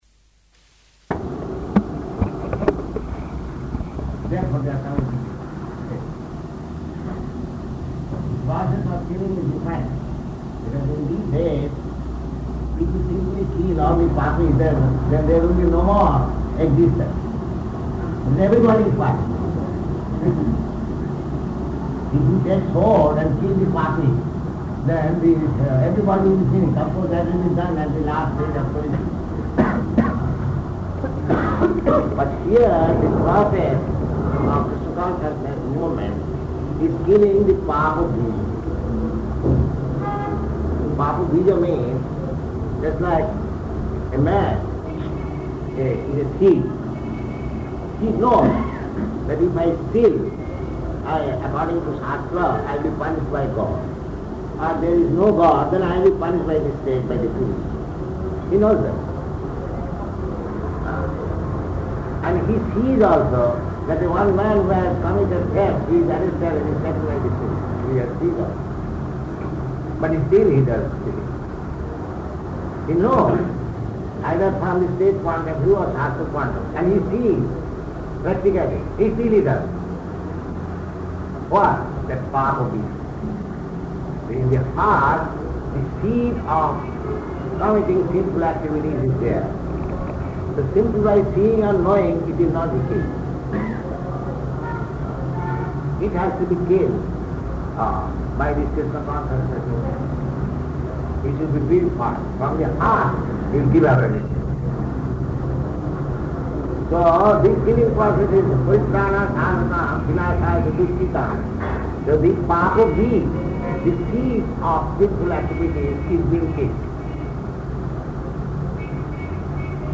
Room Conversation
Room Conversation --:-- --:-- Type: Conversation Dated: April 6th 1971 Location: Bombay Audio file: 710406R1-BOMBAY.mp3 Prabhupāda: ...therefore they are coming.